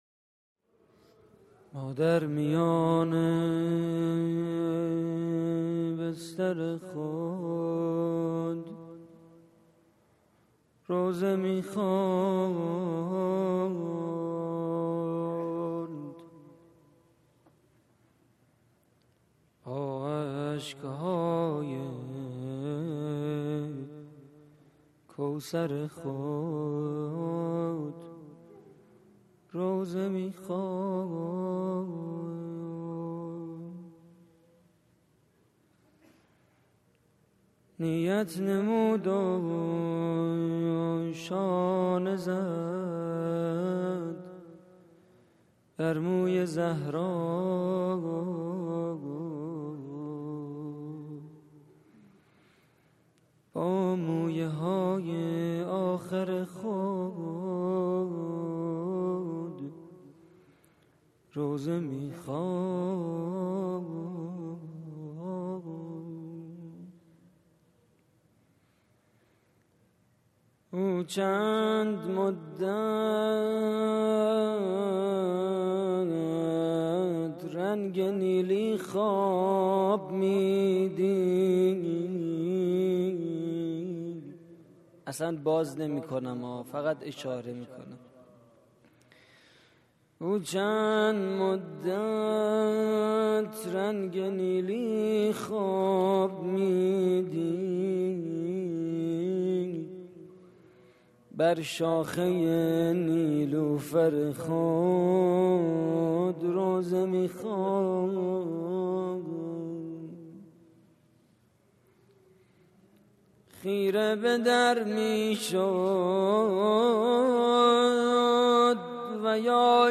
مناجات سحری ماه رمضان / هیئت الزهرا (س) دانشگاه صنعتی شریف